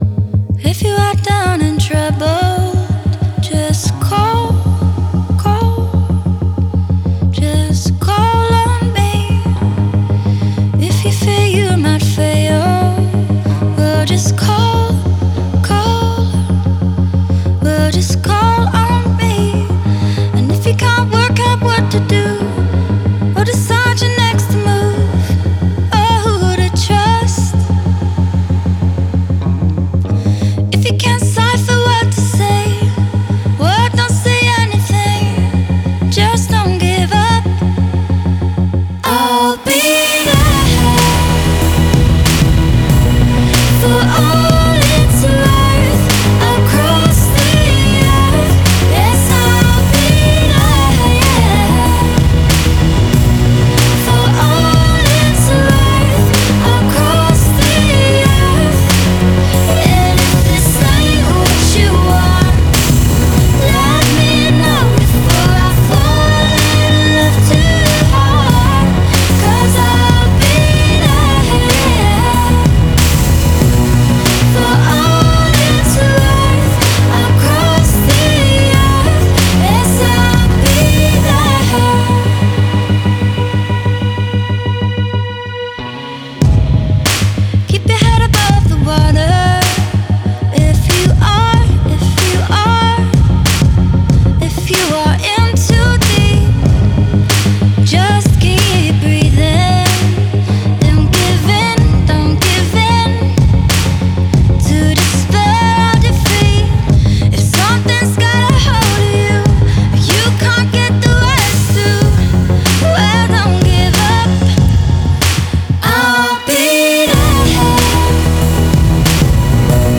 Genre: Pop, Singer-Songwriter